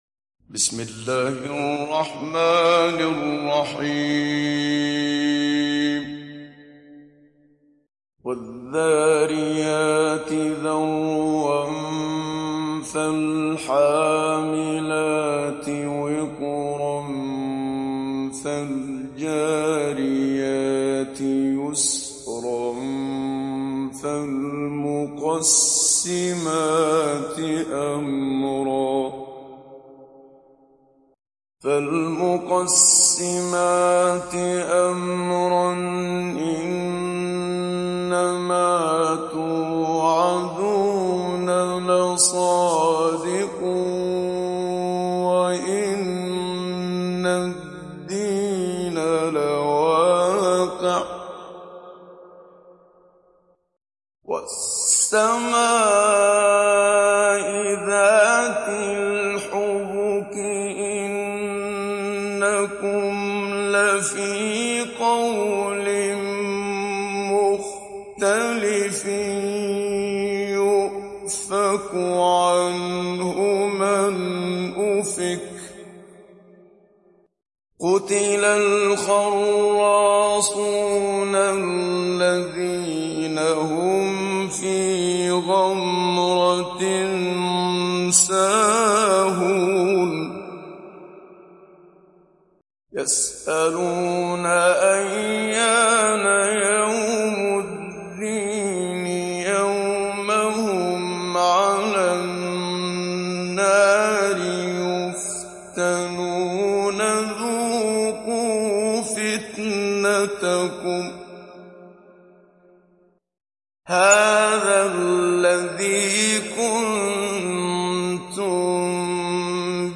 Zariyat Suresi İndir mp3 Muhammad Siddiq Minshawi Mujawwad Riwayat Hafs an Asim, Kurani indirin ve mp3 tam doğrudan bağlantılar dinle
İndir Zariyat Suresi Muhammad Siddiq Minshawi Mujawwad